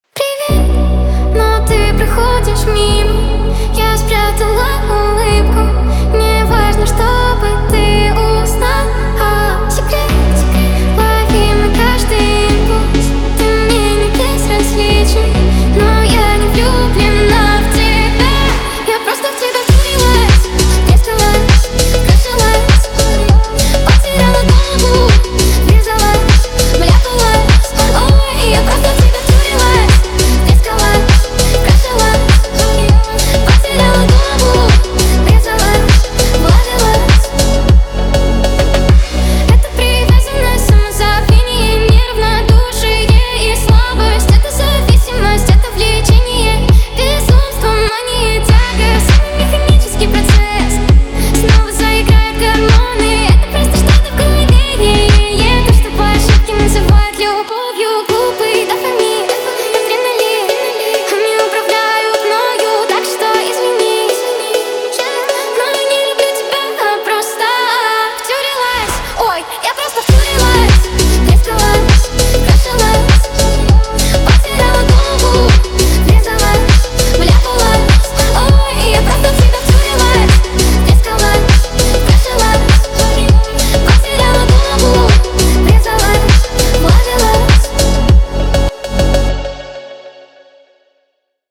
Фонк музыка
русский фонк
фонк ремиксы